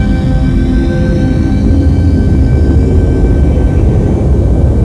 Pictures: Finale motore impuso Funzionamento Finale motore impulso Media: Propulsione in fase di manovra
propulsione_impulso.wav